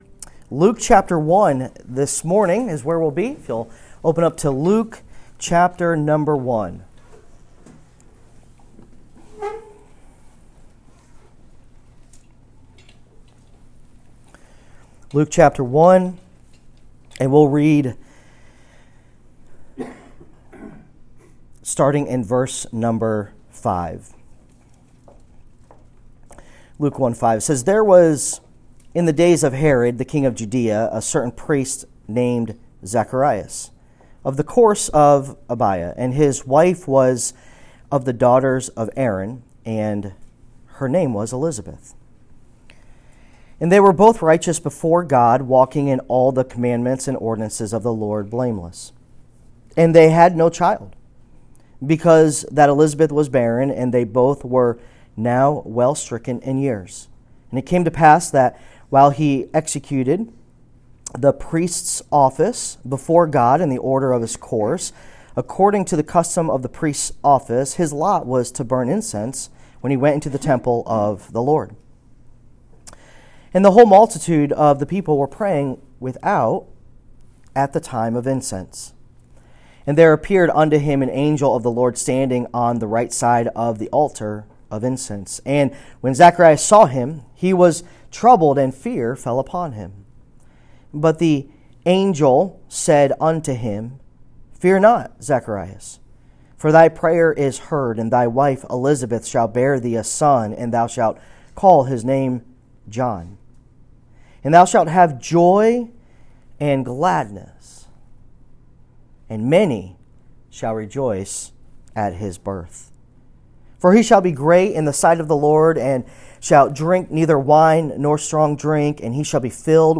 Sermons | South Middlesex Baptist Church